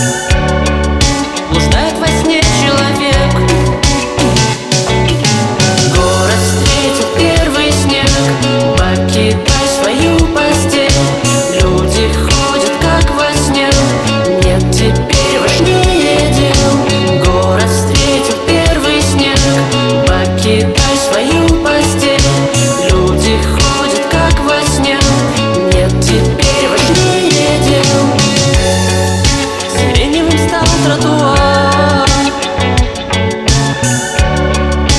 Жанр: Иностранный рок / Рок / Инди / Русские
# Indie Rock